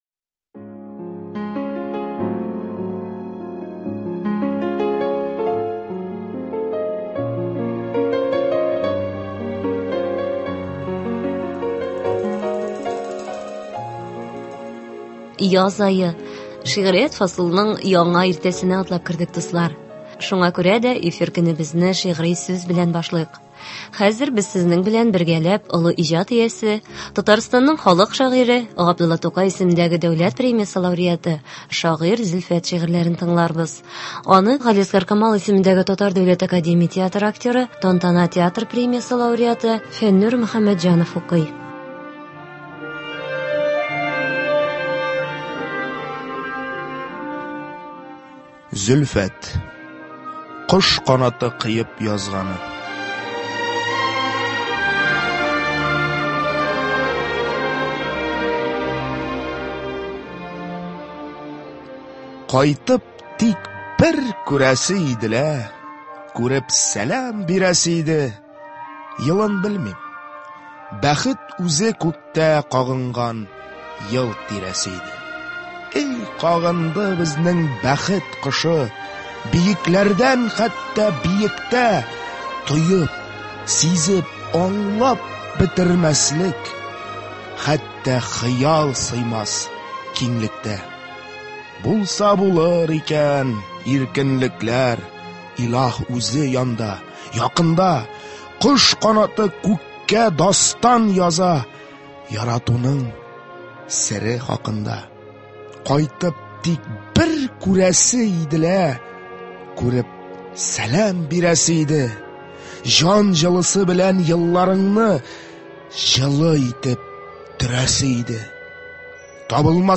Әдәби-музыкаль композиция. 18 апрель.